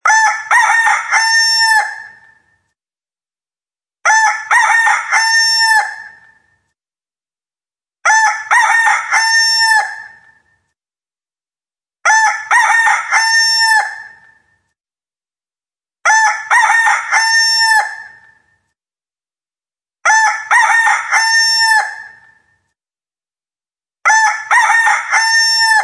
Ringetone Hanekrager
Kategori Dyr